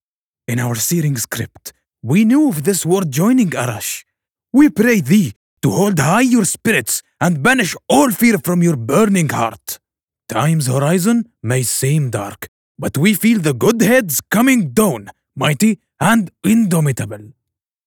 Comercial, Cálida, Empresarial, Profundo, Versátil